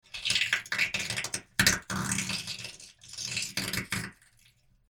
На этой странице собраны различные звуки скрежета зубами – от непроизвольного бруксизма до намеренного скрипа.
Звук записанный рядом со спящим человеком с бруксизмом